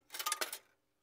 Coin Inserted into Slot